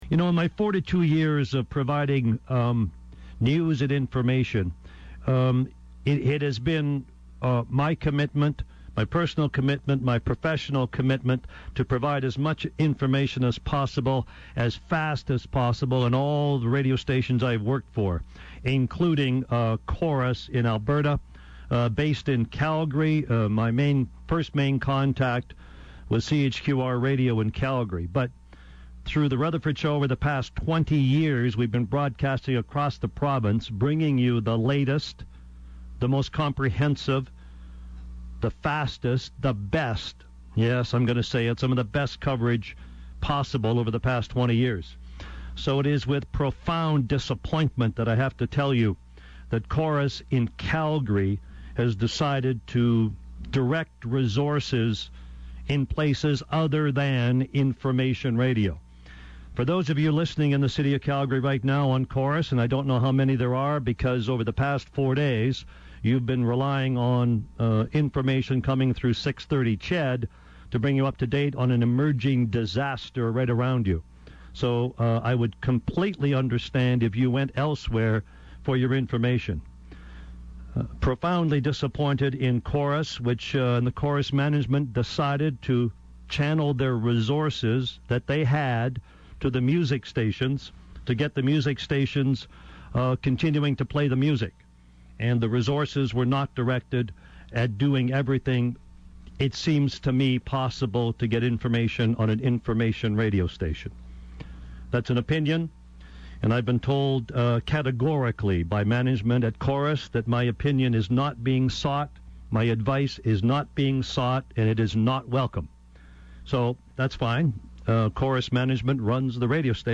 The “Rant”